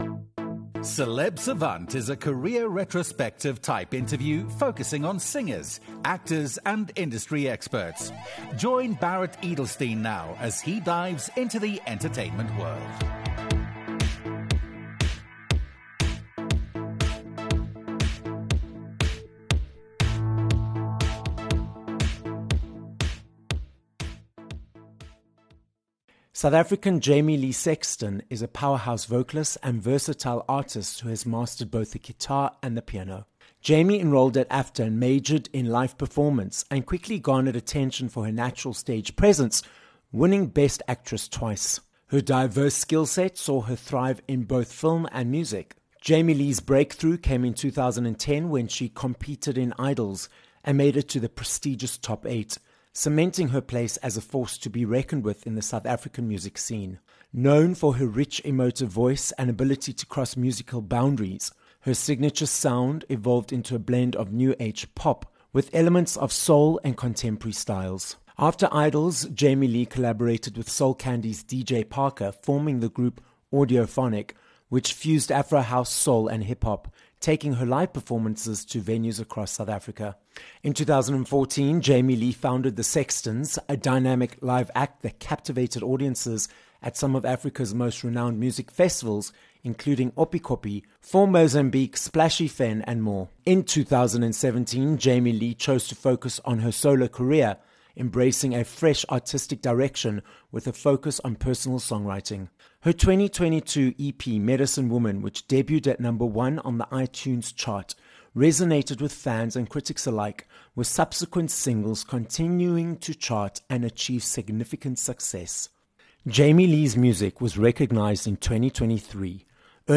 We also discuss NLP, the current landscape of the music industry and more. This episode of Celeb Savant was recorded live in studio at Solid Gold Podcasts, Johannesburg, South Africa.